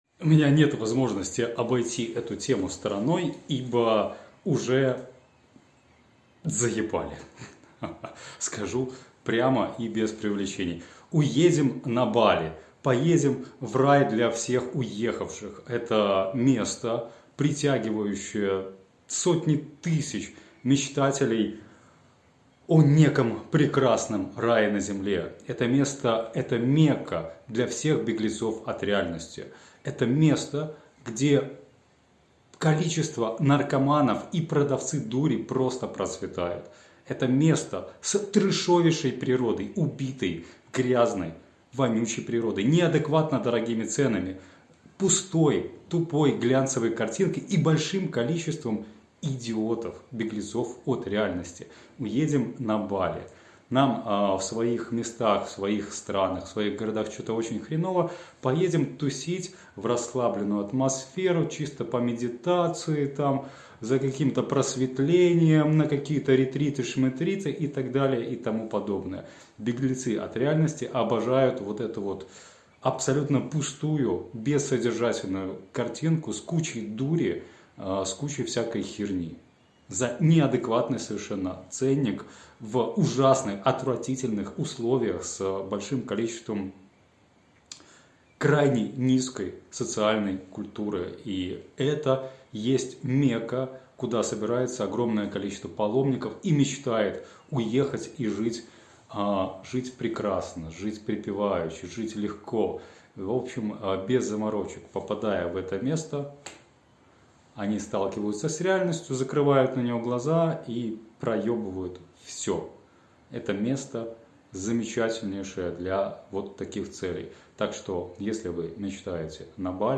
Голосовая заметка